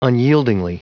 Prononciation du mot : unyieldingly
unyieldingly.wav